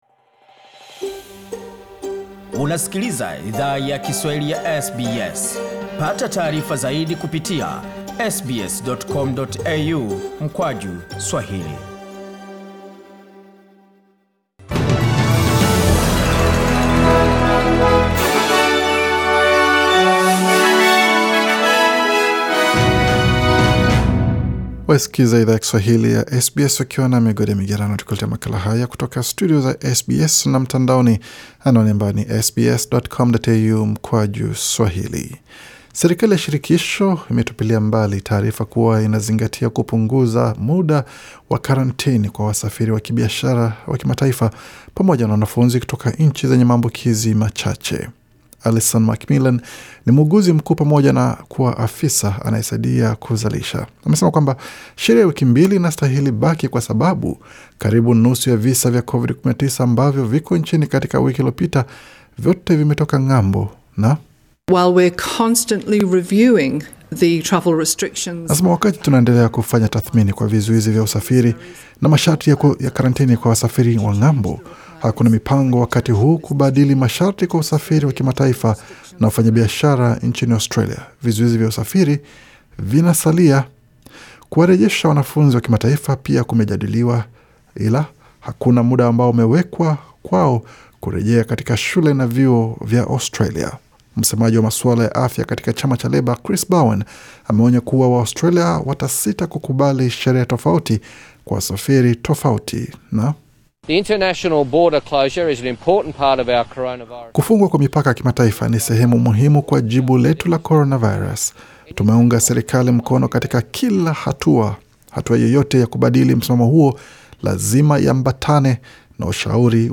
Taarifa ya habari 14 Juni 2020